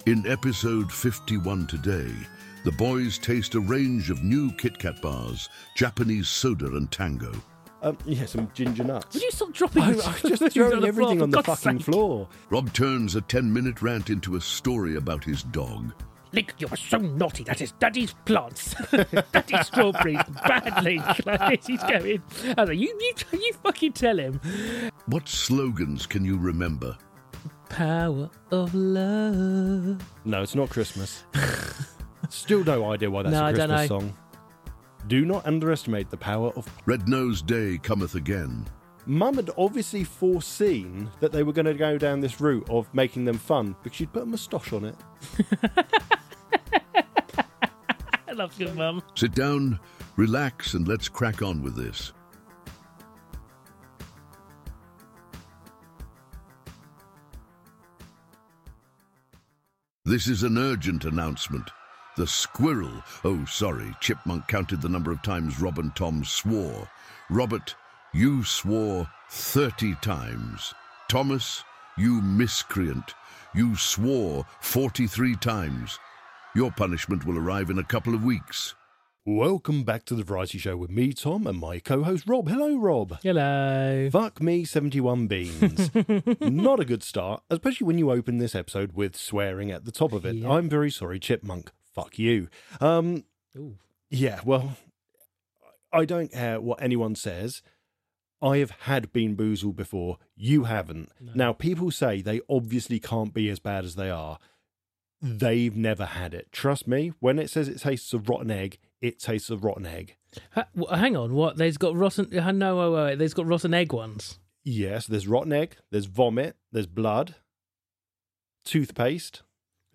From nostalgic trips down memory lane to the latest in film, music, gaming, and even parenting, they cover it all. Each episode is packed with taste tests, quizzes, and spirited discussions that will keep you entertained and asking 'how did we get here?'.